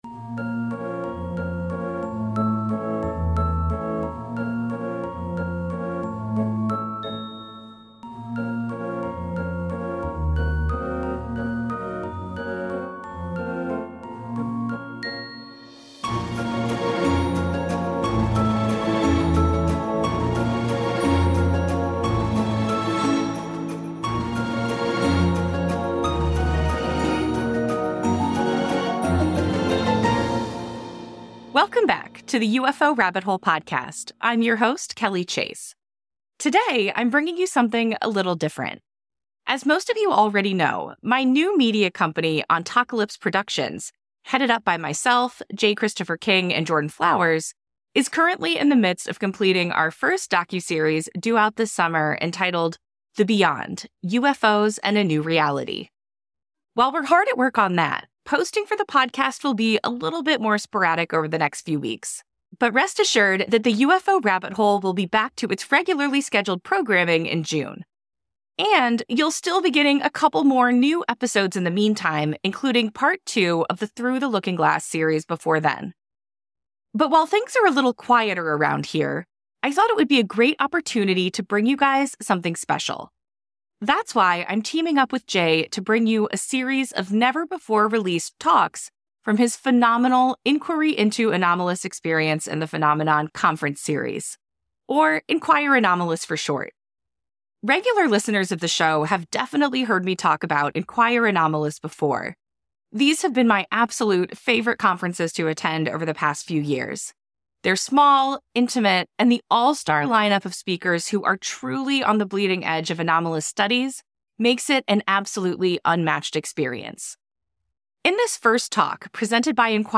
In this first talk presented by Inquire Anomalous, we’ll be hearing from Tim Gallaudet. Tim Gallaudet is a retired Rear Admiral in the U.S. Navy, and a career oceanographer.